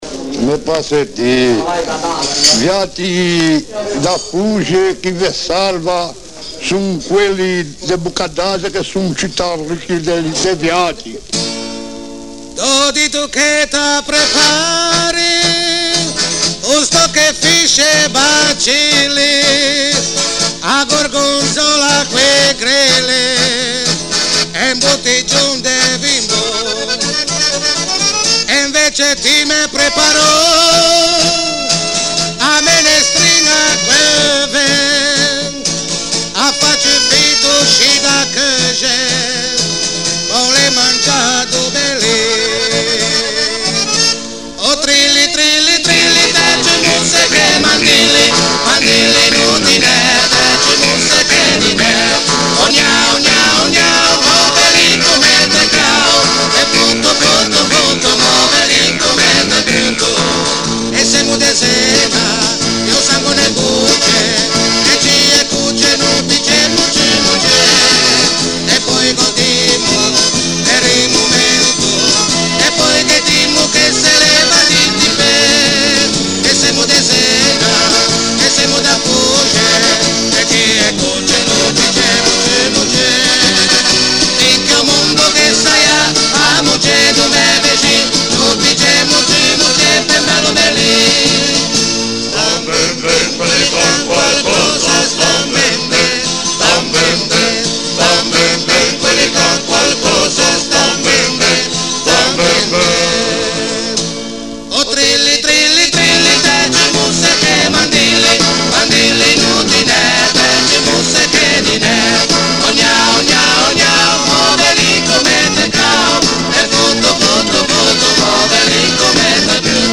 Altre musiche e canti popolari tradizionali:
Trallallero